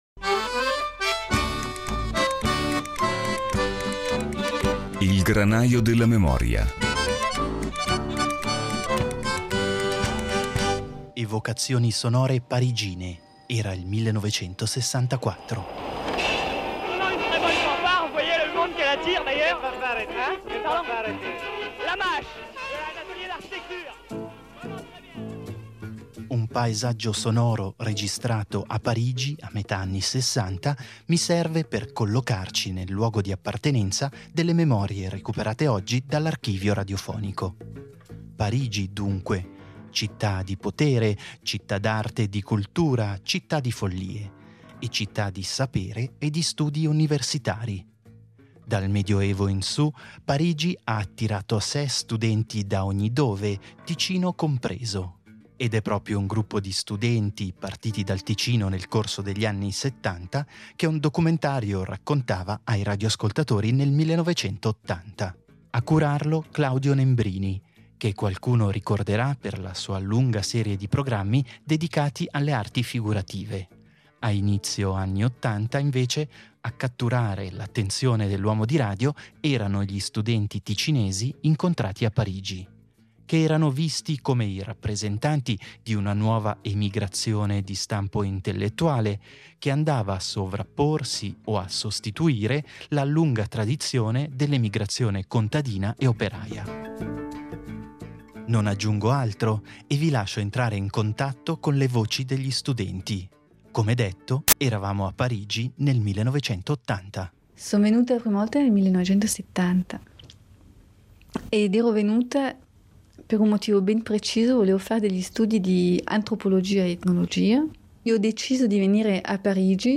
All’inizio degli anni Ottanta il microfono della RSI si aggirava per le strade di Parigi. Oggetto della sua curiosità era una categoria particolare di giovani ticinesi che, per continuare i propri studi, si erano temporaneamente trasferiti nella capitale francese. Curioso a sua volta, il "Granaio della memoria" ha recuperato il materiale sonoro registrato in Francia e tenterà di rievocare le vicende e le esperienze degli studenti ticinesi di Parigi del 1980.